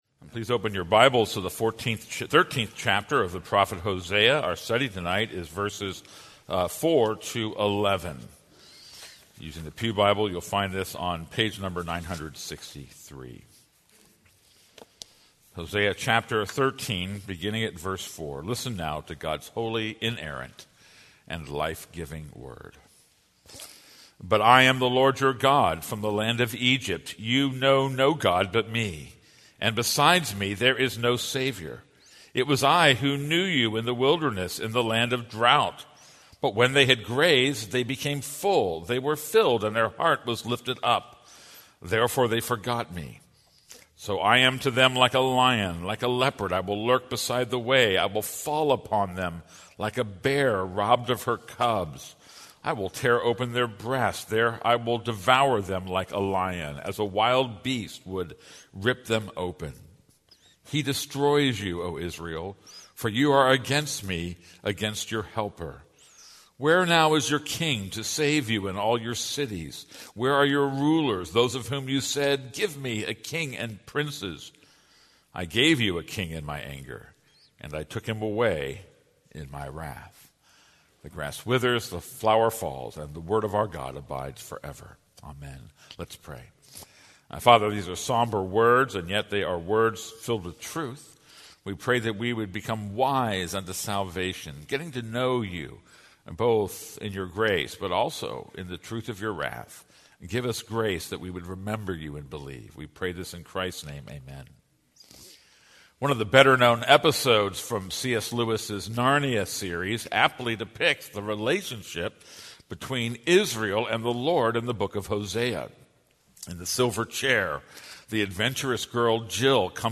This is a sermon on Hosea 13:4-11.